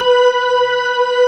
Index of /90_sSampleCDs/AKAI S6000 CD-ROM - Volume 1/VOCAL_ORGAN/POWER_ORGAN